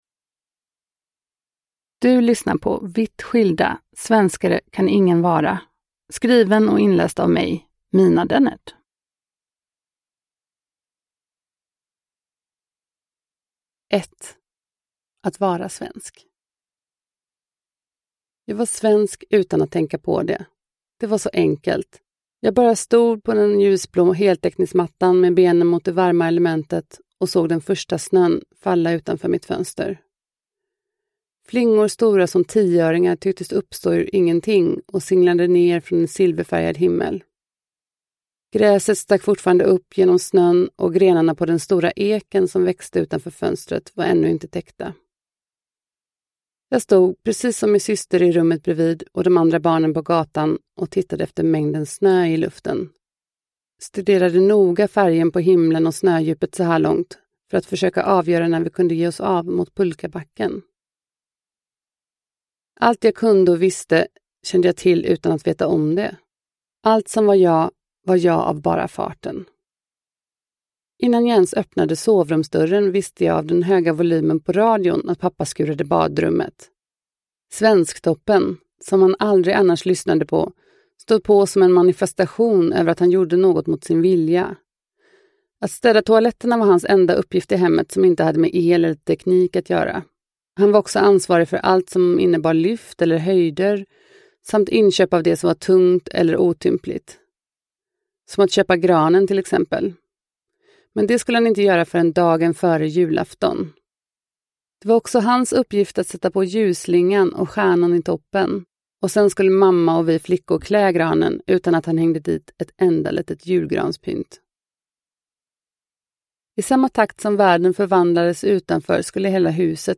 Vitt skilda : Svenskare kan ingen vara – Ljudbok – Laddas ner